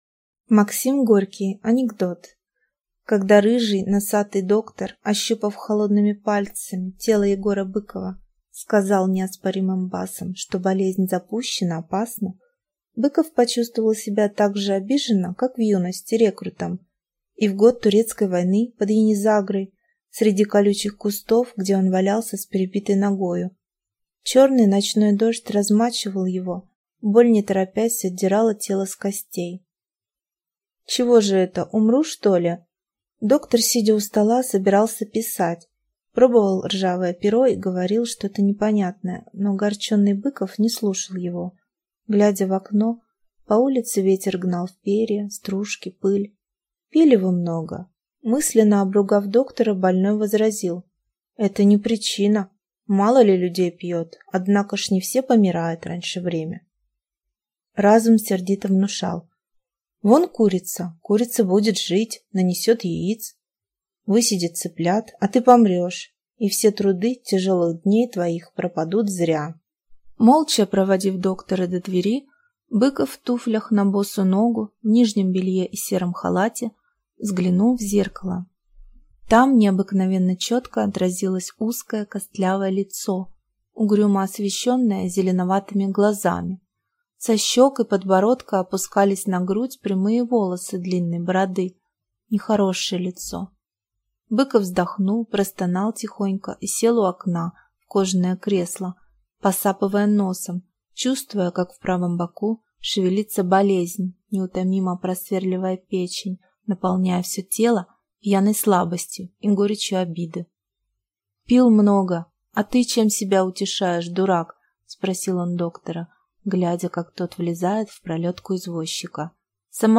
Aудиокнига Анекдот